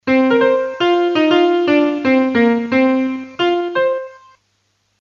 描述：C小调快节奏的摇摆爵士钢琴。
低音符可能会被改编成直立式贝司的漂亮线条。
标签： 爵士乐 midi 钢琴 摇摆乐
声道立体声